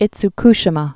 (ĭts-kshĭ-mə)